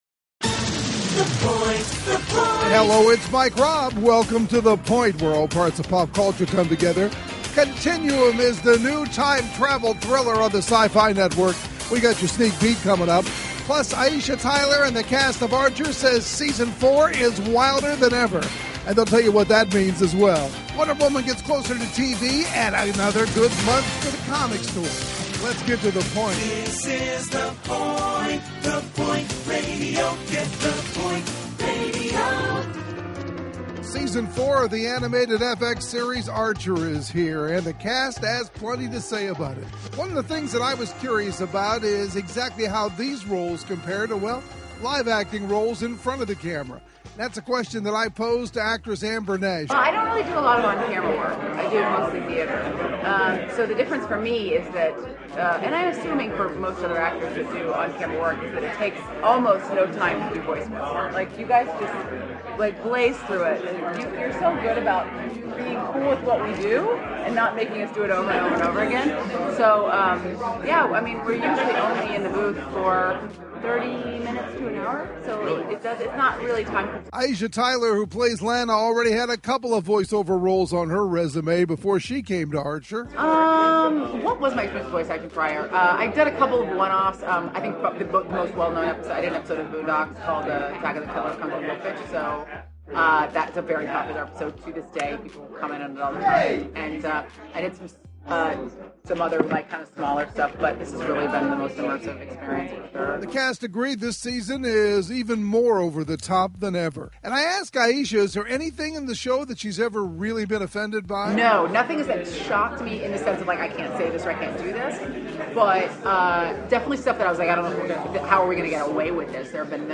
Continuum - The Point Radio: Rachel Nichols & Simon Barry Audio Interview
Also interviewed is the series creator Simon Barry . [The Continuum interviews begin at approximately the 8:30 mark in the broadcast.]